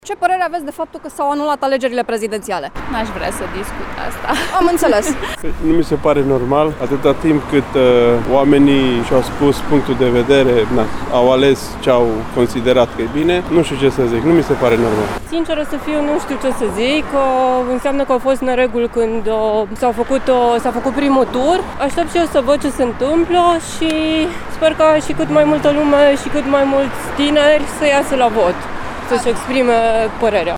Informația, potrivit căreia Curtea Constituțională a decis să anuleze în integralitate procesul electoral pentru alegerea președintelui, a luat prin surprindere câțiva timișoreni, care încă erau în timpul serviciului sau mergeau către facultate.